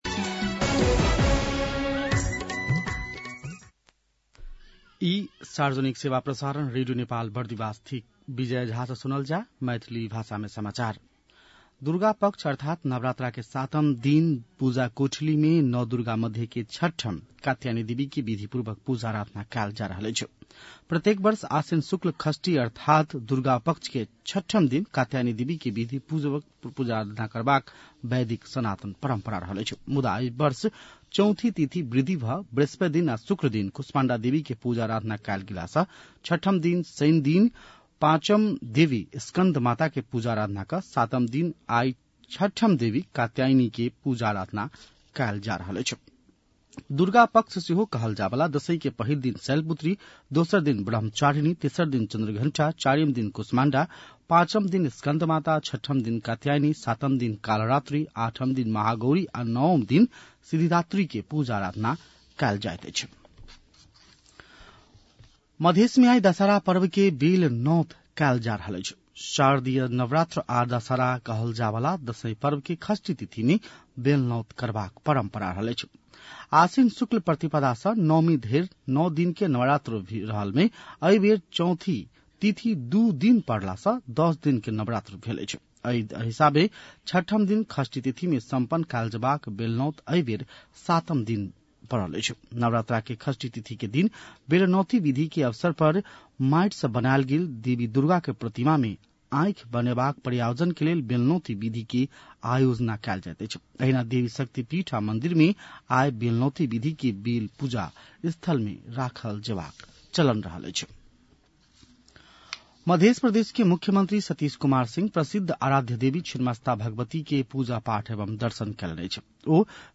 मैथिली भाषामा समाचार : १२ असोज , २०८२
6-pm-maithali-news-6-12.mp3